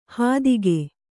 ♪ hādige